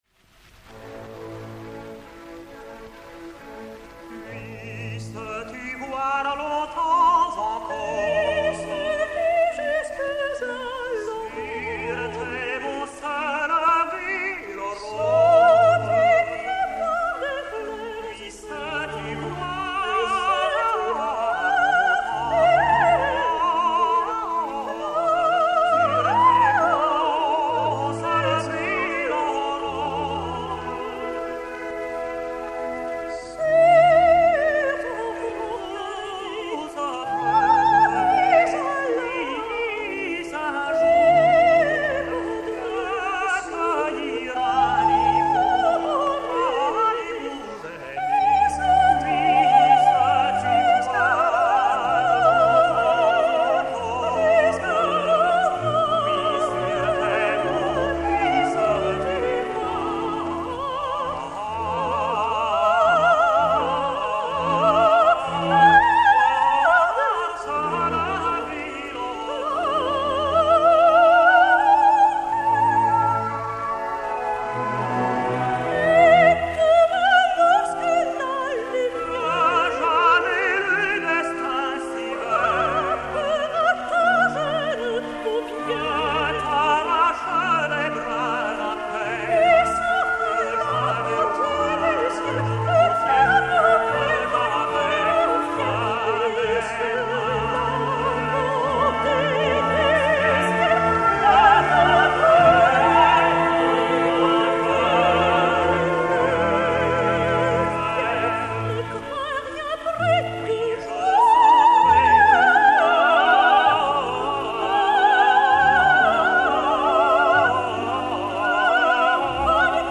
Extraits enregistrés en 1958 (révision musicale d'Henri Büsser) :
Chœurs et Orchestre National de la Radiodiffusion Française dir. Georges Tzipine (chef des chœurs René Alix)
Acte I - Duo (Marie - Le Jeune Bulgare)